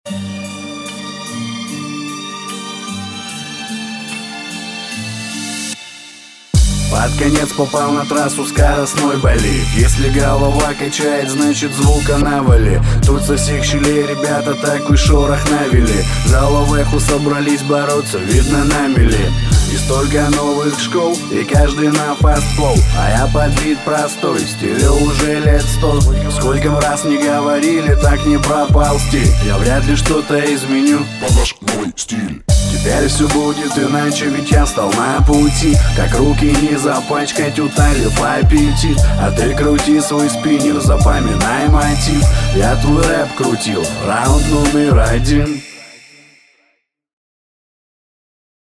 Есть приятные моменты, но разбиения рифм по типу "пра-пал-зти" и "на пу-ти" ужасно звучат.